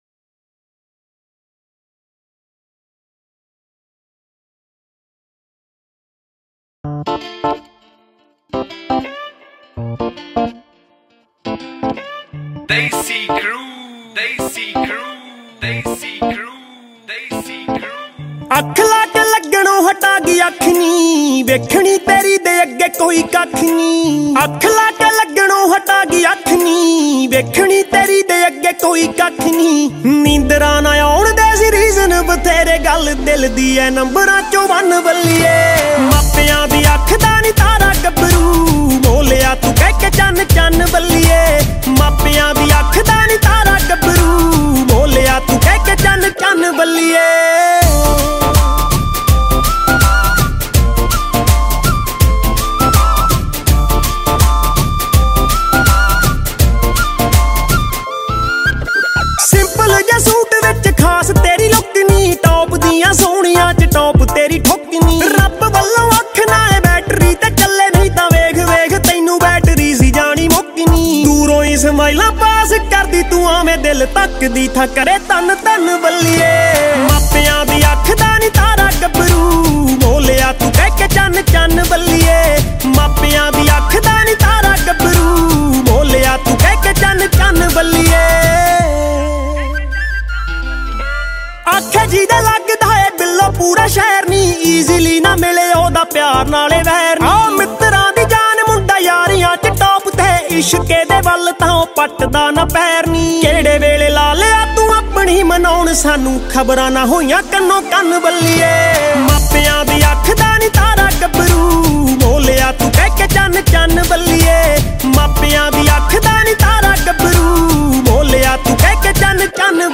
Mp3 Files / Bhangra /